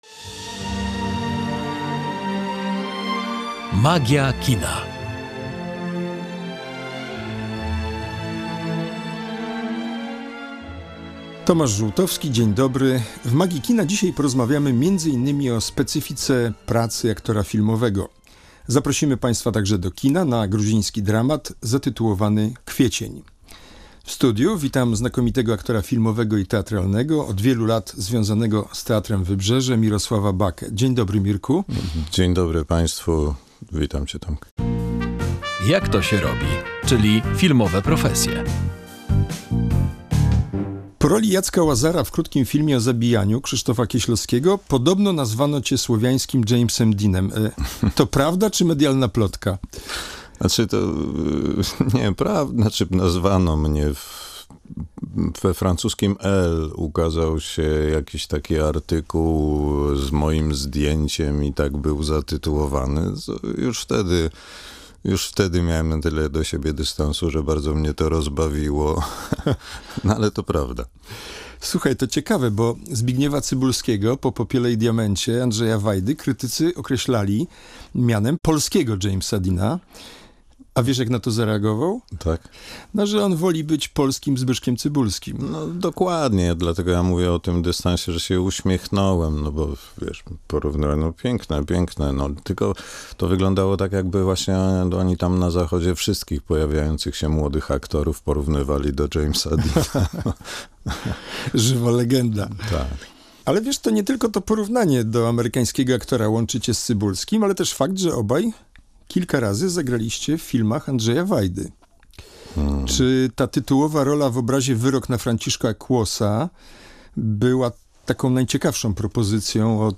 Mirosław Baka, wybitny aktor filmowy i teatralny, gościem „Magii Kina”